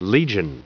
Prononciation du mot legion en anglais (fichier audio)
Prononciation du mot : legion